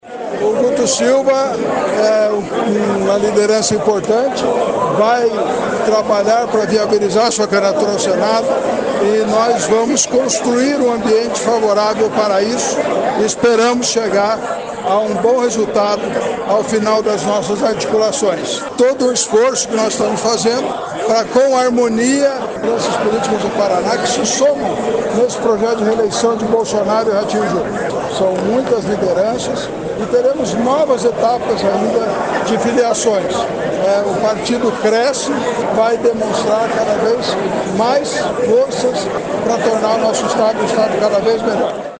Player Ouça Ricardo Barros, líder do governo na Câmara